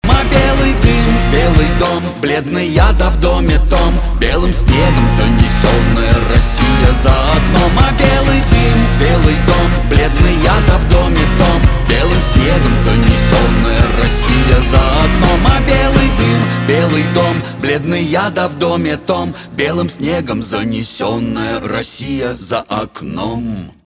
русский рэп
гитара , пианино